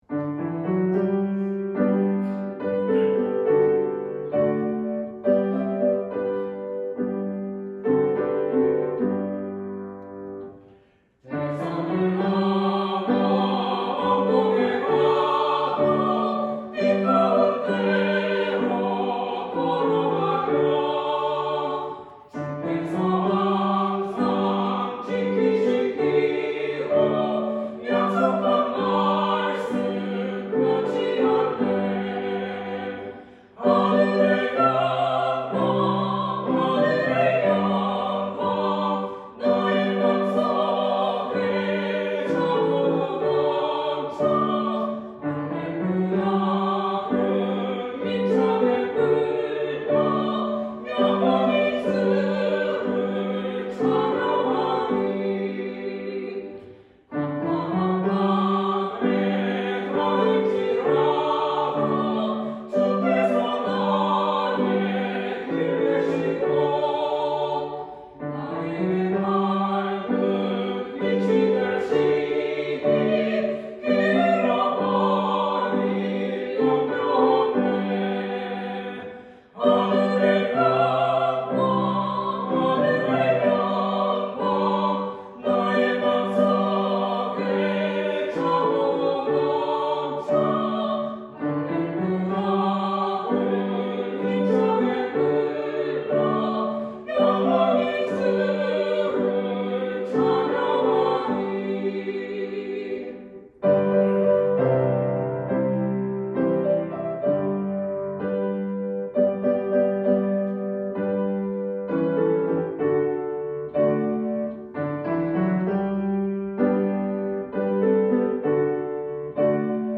성가대